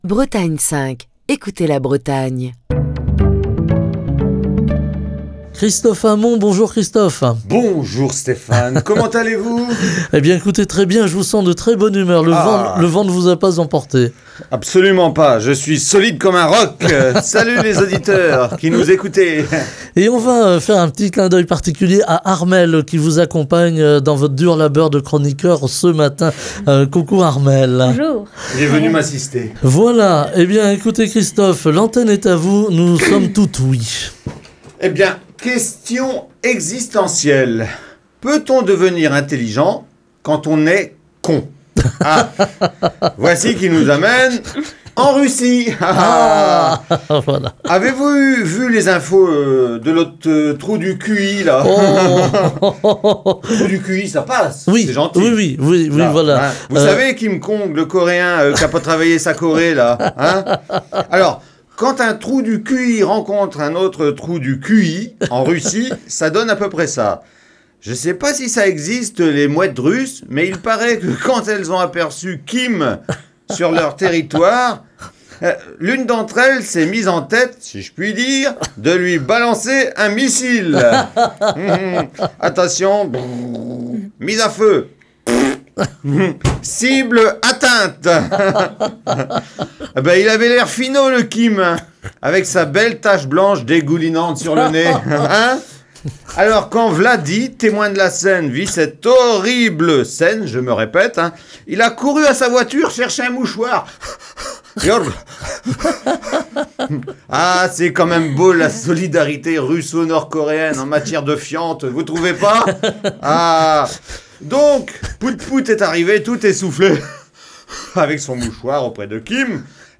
Chronique du 3 novembre 2023.